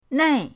nei4.mp3